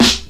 • 90's Crispy Rap Snare Sound A# Key 31.wav
Royality free snare drum sound tuned to the A# note. Loudest frequency: 2783Hz
90s-crispy-rap-snare-sound-a-sharp-key-31-sSk.wav